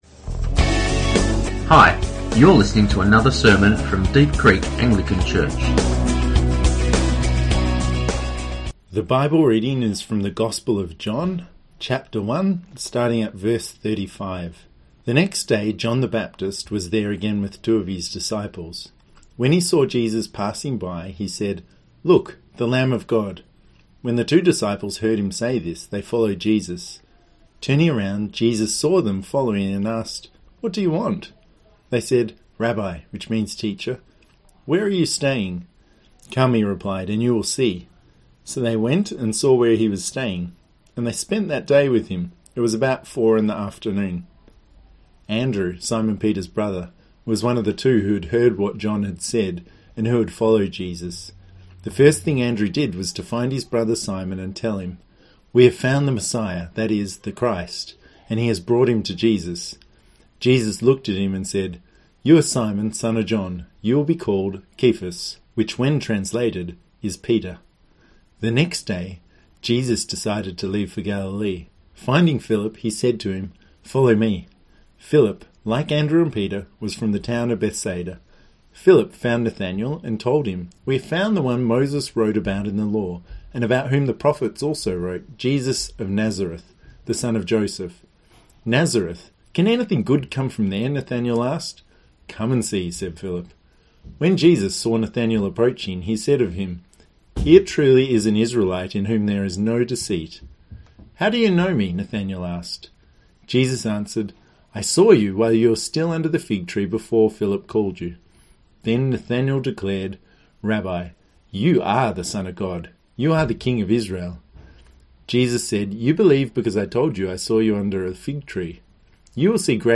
Sermons | Deep Creek Anglican Church